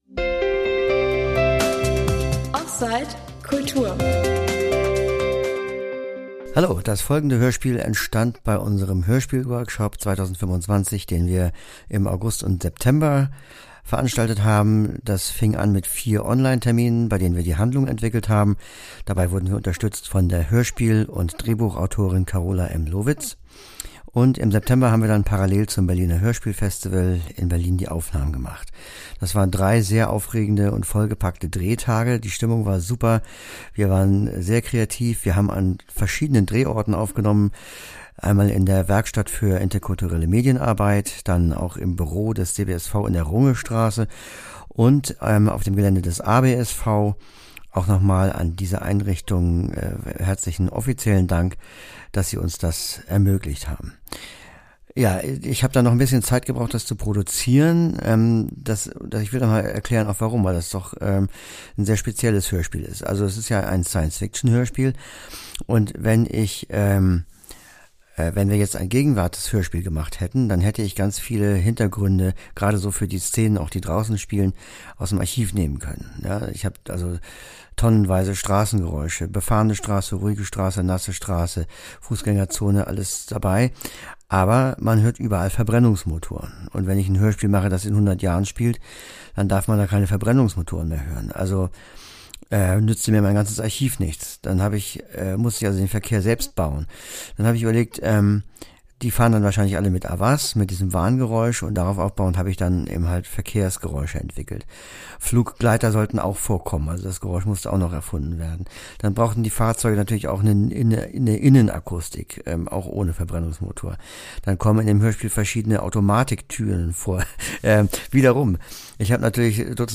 Die Unheilbaren: Science-Fiction-Hörspiel, entstanden im offSight-Hörspiel-Workshop 2025 ~ offSight - DBSV Podcast